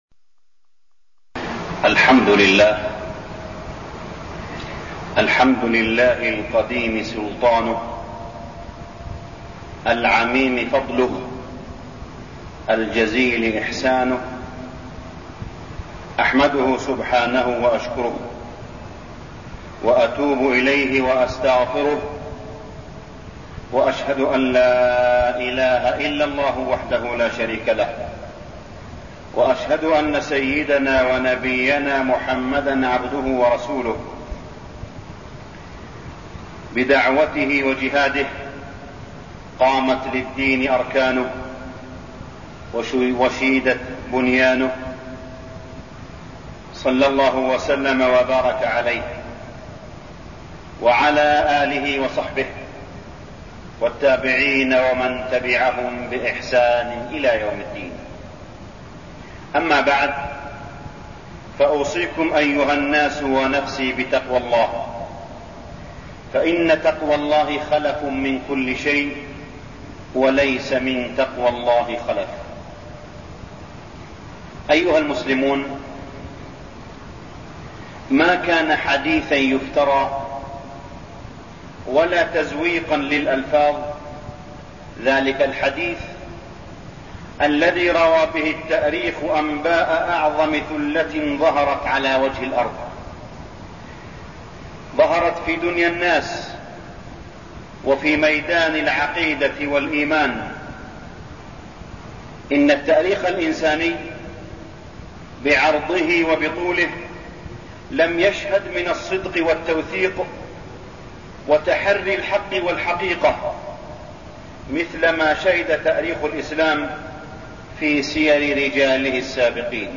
تاريخ النشر ٢٢ محرم ١٤١٥ هـ المكان: المسجد الحرام الشيخ: معالي الشيخ أ.د. صالح بن عبدالله بن حميد معالي الشيخ أ.د. صالح بن عبدالله بن حميد سيرة الصديق رضي الله عنه